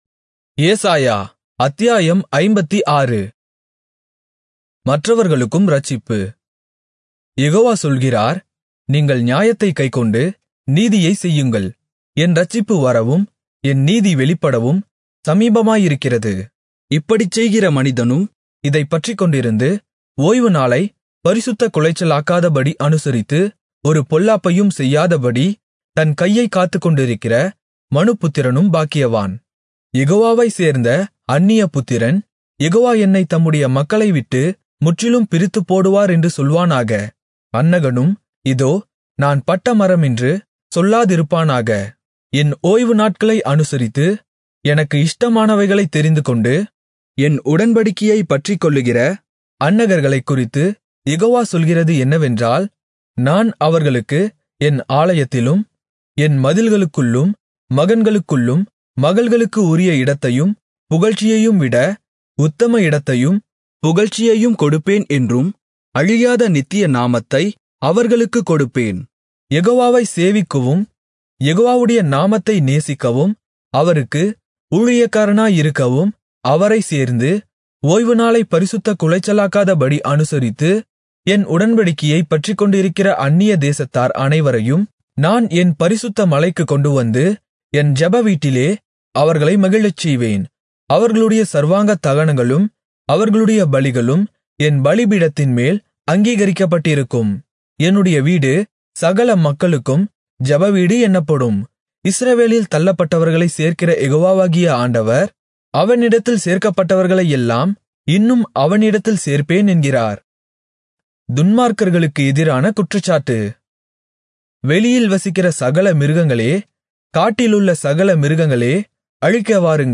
Tamil Audio Bible - Isaiah 10 in Irvta bible version